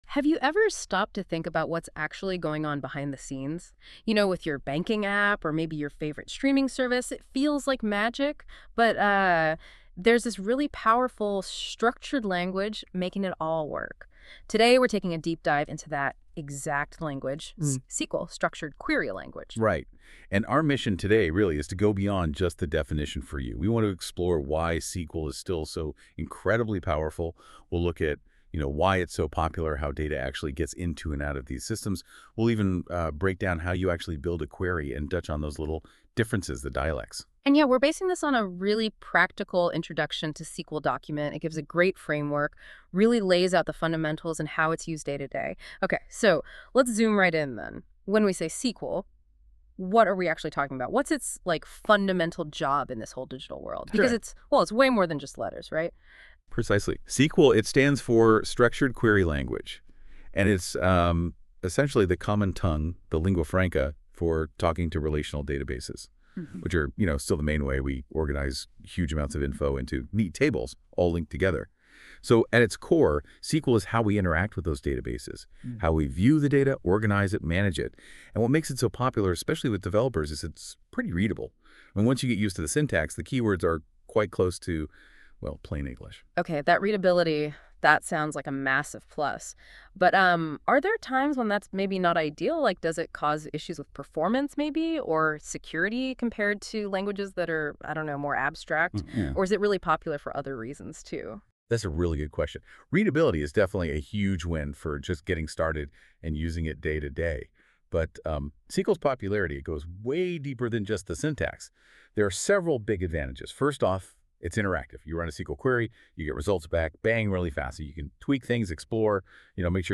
Activity: Listen to This Podcast That was created using AI from these materials.